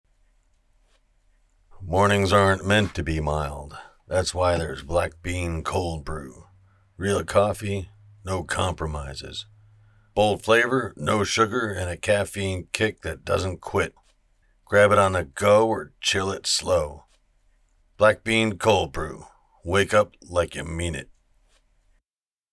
Coffee Commercial
Senior